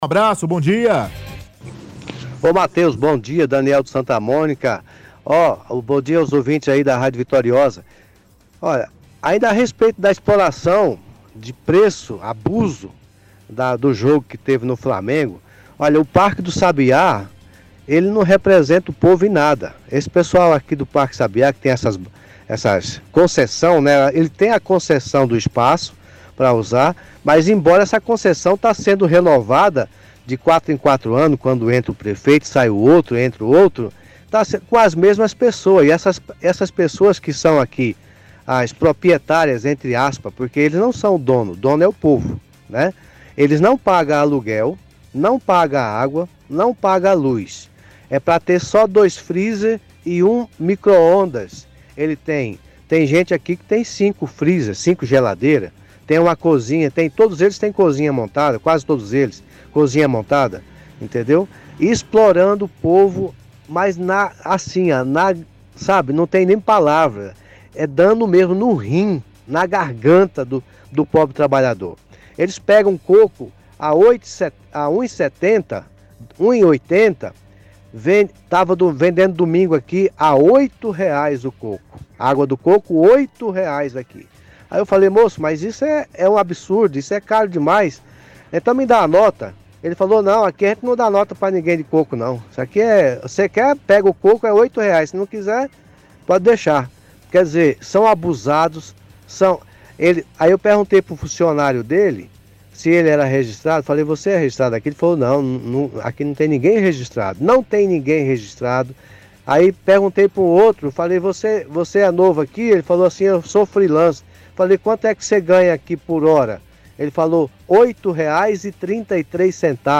– Ouvinte reclama dos comerciantes que fica no parque do sabiá, fala que os quiosques que vende produtos dentro do parque está com abuso de preços.